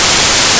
TV-Channel-Change
channel static television TV sound effect free sound royalty free Movies & TV